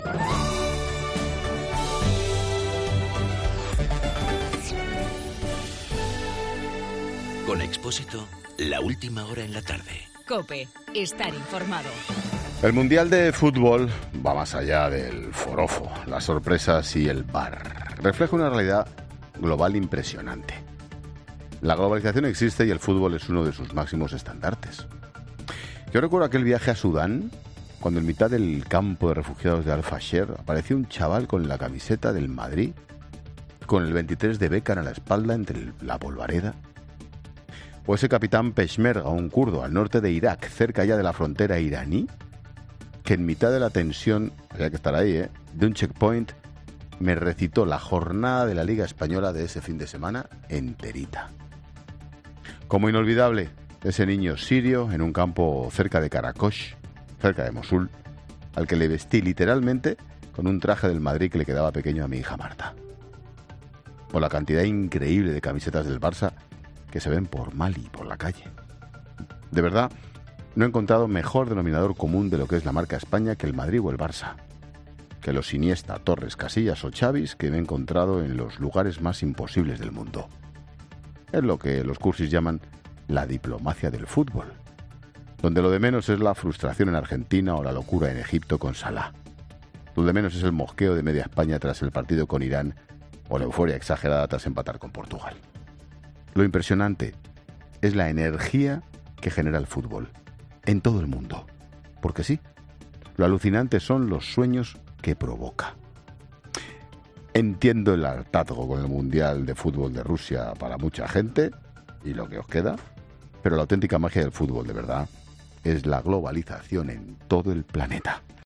Monólogo de Expósito
El comentario de Ángel Expósito.